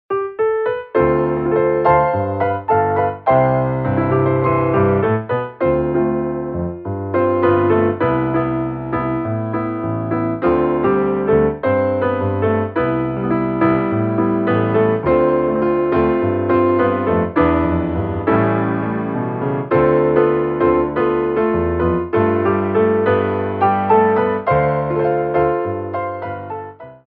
TANGO
8x8 (Slow)